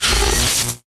lightning-attractor-charge-3.ogg